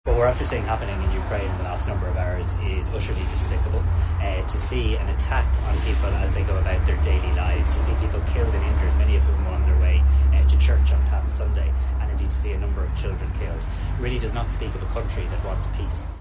Simon Harris says it's a "cowardly" attack - and "not the actions of a country seeking peace":